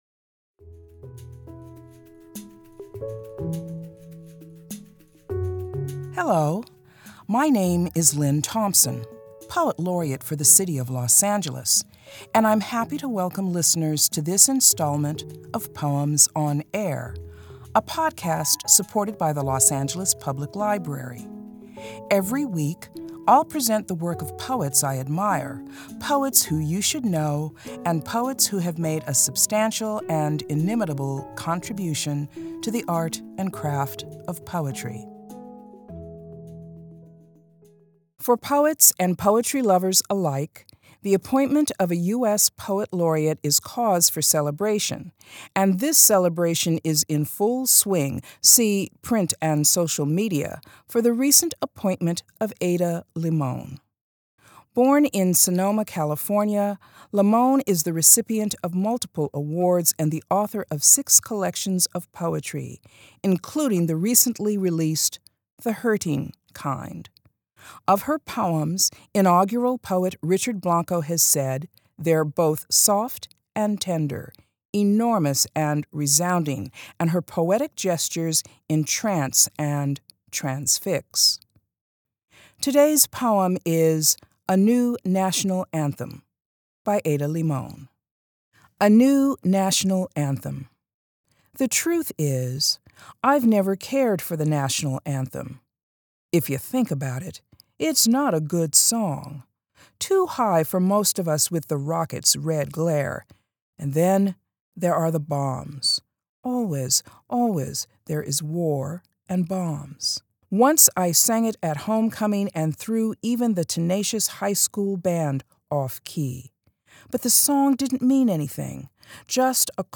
Los Angeles Poet Laureate Lynne Thompson reads Ada Limón's poem "A New National Anthem."